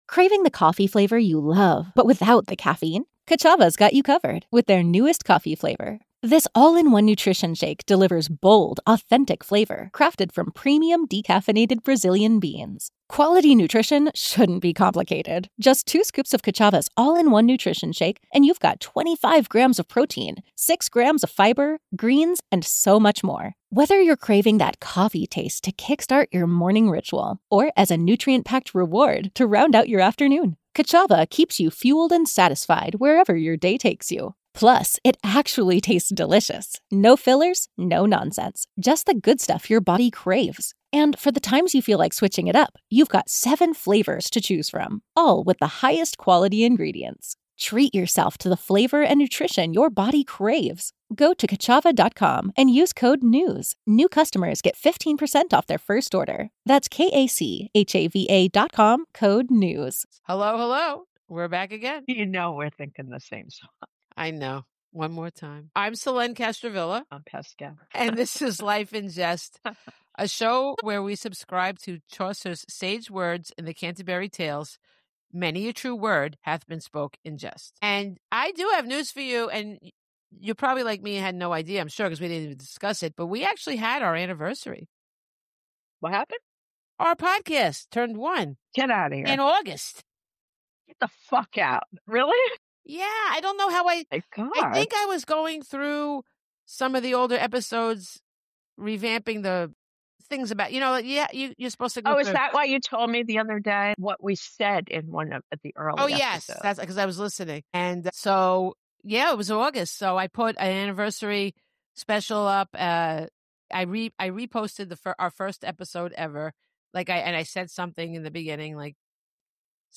They let loose on the movies that should never have been made, in their signature snarky style.
🎥 Laughter, nostalgia, and a touch of pop culture PTSD await in this episode of Life in Jest.